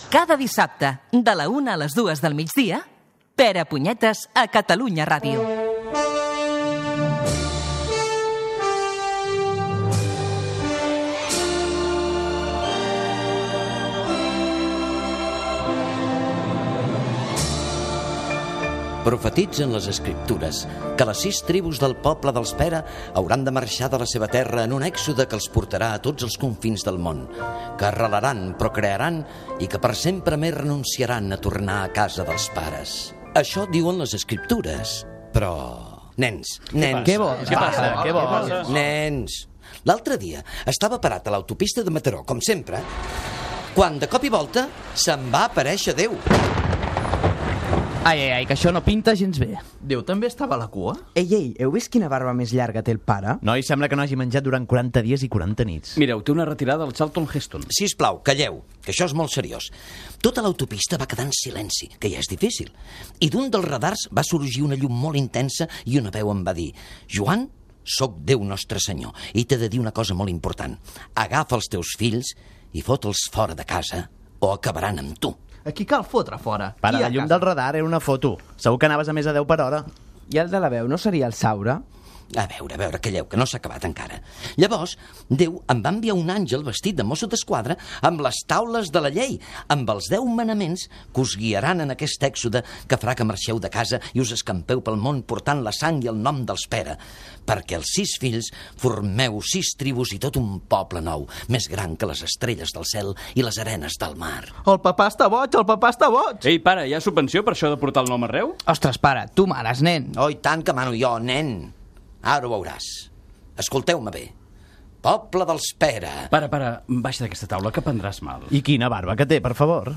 Careta del programa
Gènere radiofònic Entreteniment